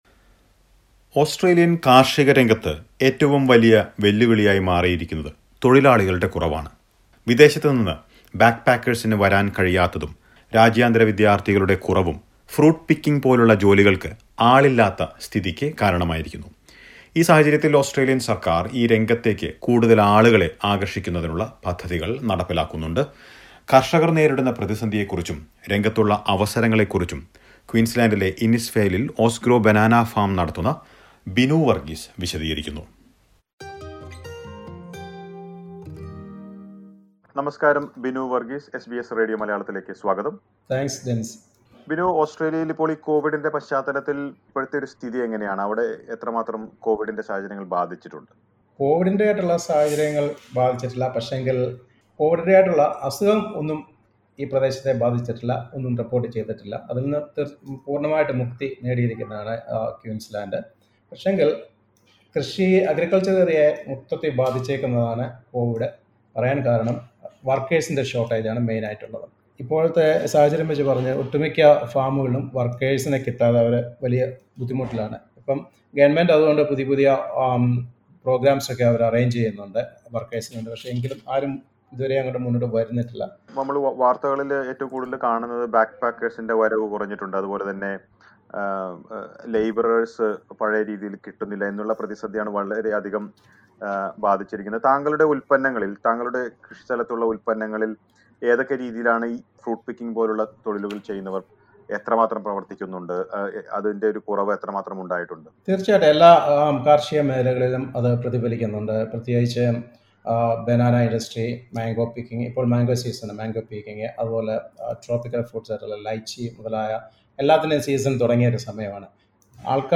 As overseas travellers are restricted to Australia the farming industry has a shortage of workers. But this has opened more jobs for the local community. Listen to a report.